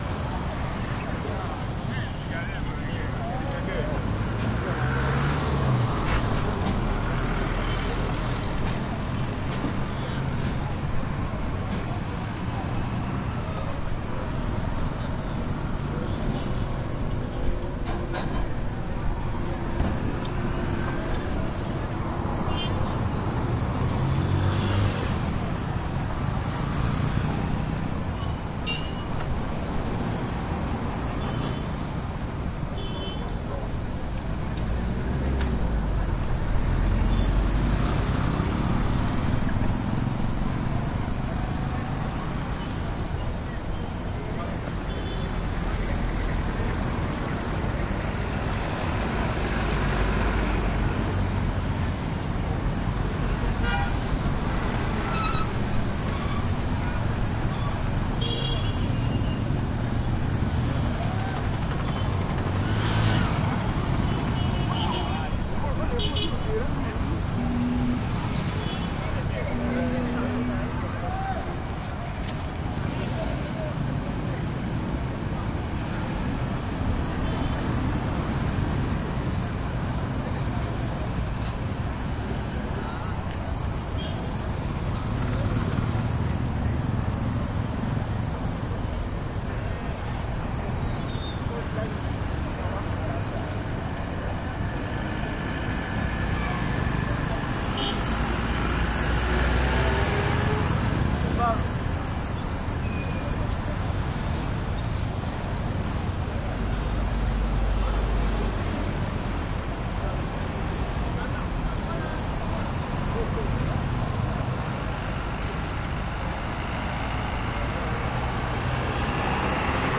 乌干达 " 交通 繁忙的交通圆环由市场悸动的汽车摩托车轻便摩托车和人 坎帕拉，乌干达，非洲 2
描述：交通繁忙的交通圈环形交通枢纽市场嘶嘶的汽车摩托车轻便摩托车和人民坎帕拉，乌干达，非洲2016.wav
Tag: 非洲 乌干达 环岛 交通 街道 轻便摩托车 汽车 摩托车 城市